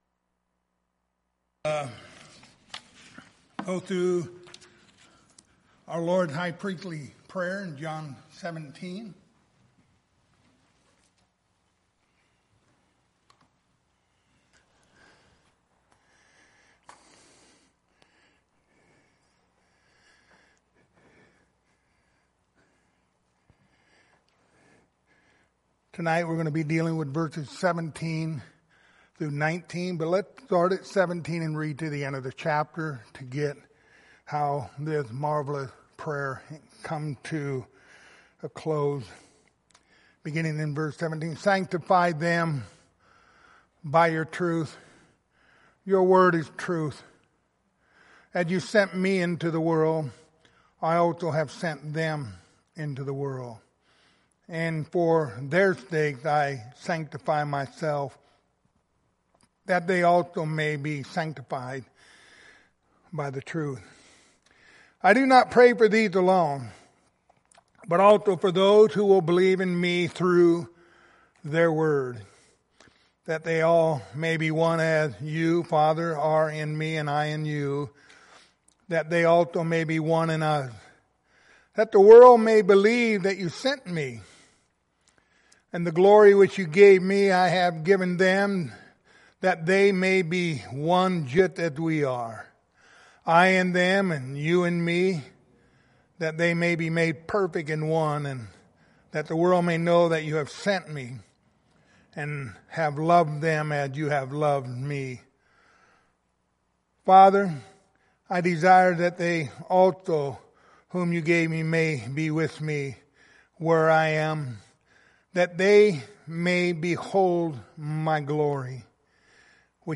Passage: John 17:17-19 Service Type: Wednesday Evening